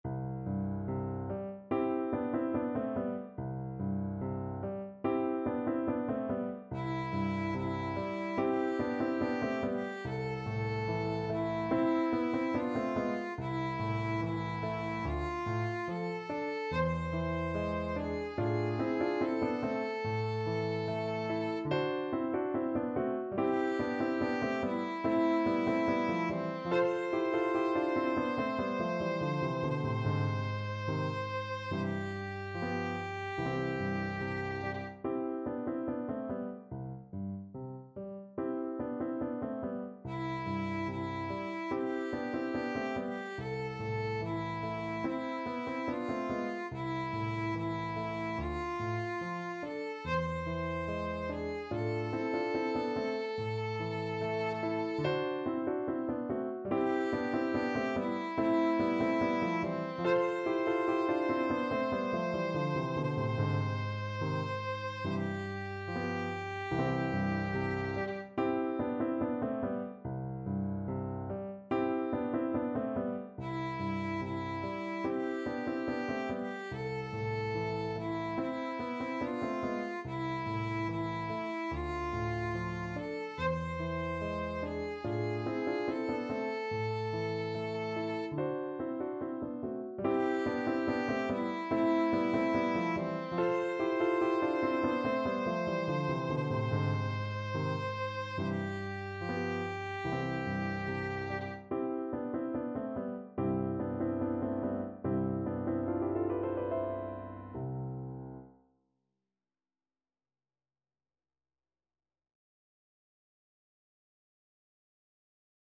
Key: C major
Tempo Marking: Allegretto
Style: Classical